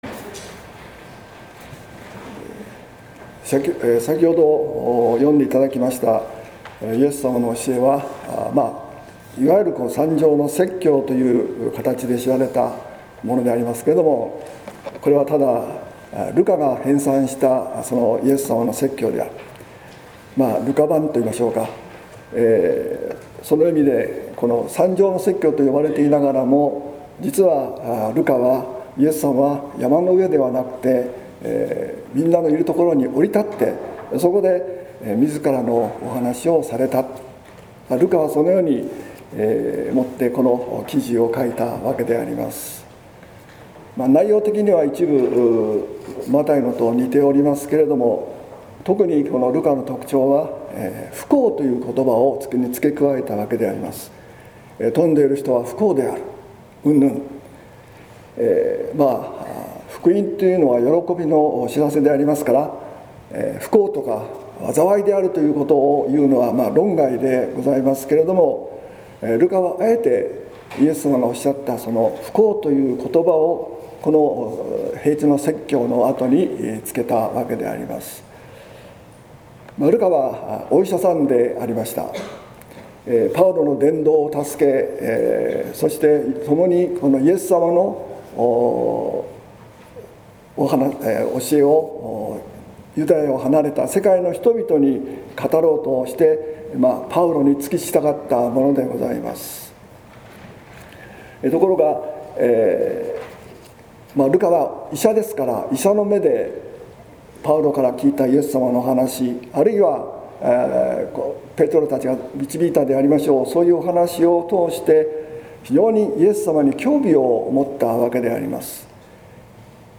説教「平地に立つイエス」（音声版）